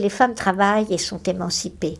schwa_travaillent et sont emancipees.wav